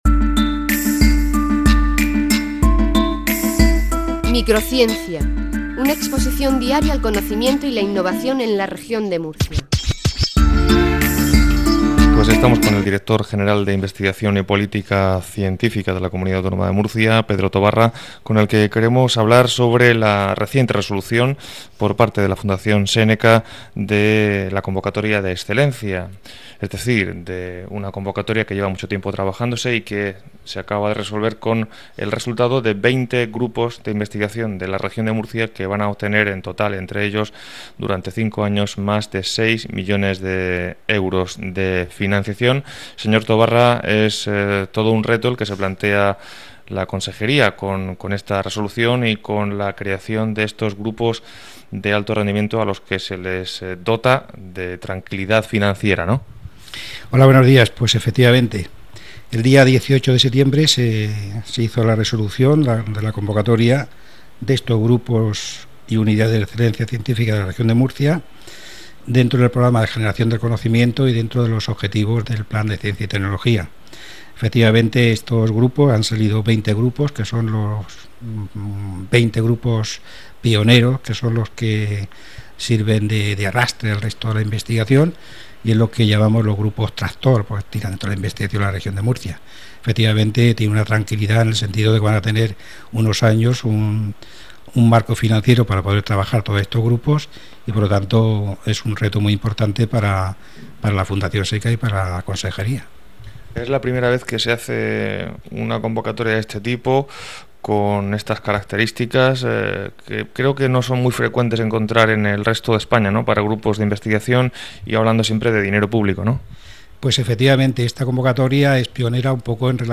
Dentro del Programa de Comunicación Pública de la Ciencia y la Tecnología, la Fundación Séneca patrocina el programa de radio "Microciencia", emitido a través de Onda Regional de Murcia.
Pedro Tobarra; D.G. de investigación y política científica.